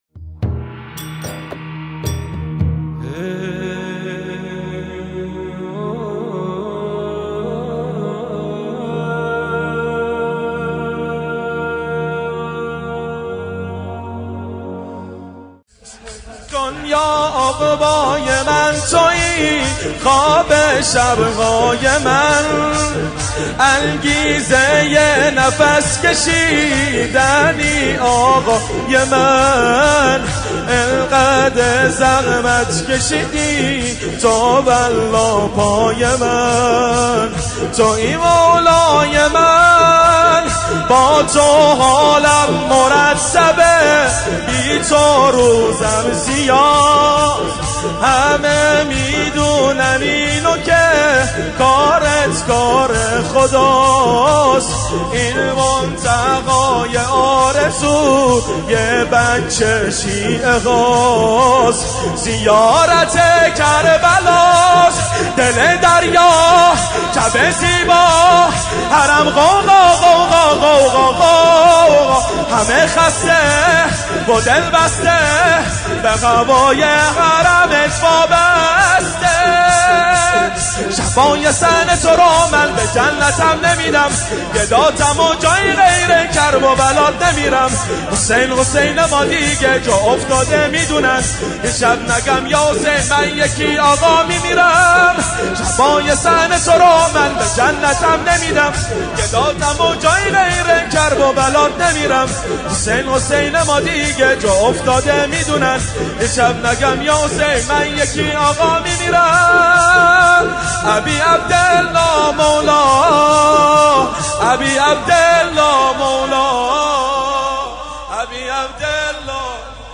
شور | دنیا عُقبای من تویی، خواب شب‌های من
مداحی
هیأت علی اکبر بحرین